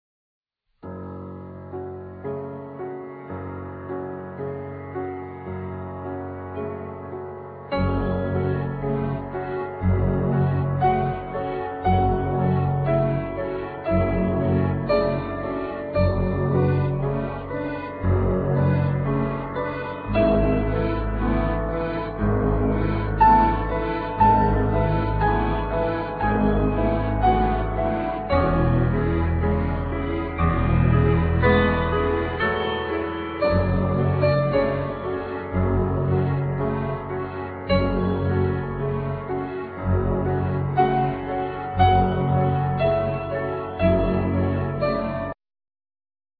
Keyboards
Guitar,Flute
Bass guitar
Drums